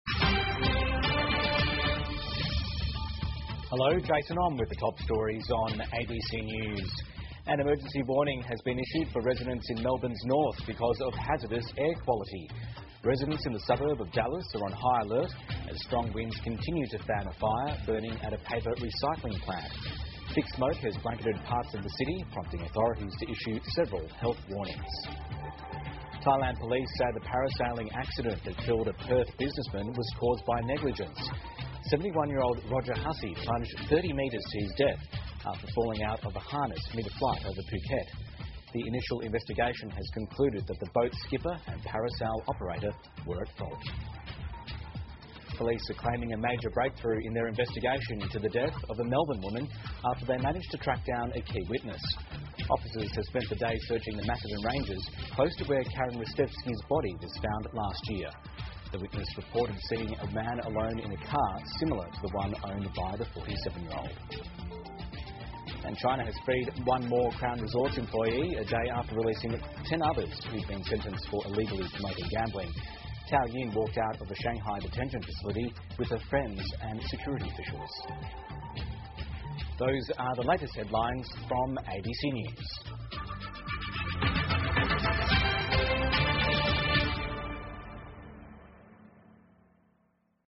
澳洲新闻 (ABC新闻快递) 墨尔本工厂突发大火 中国释放澳皇冠度假集团涉赌员工 听力文件下载—在线英语听力室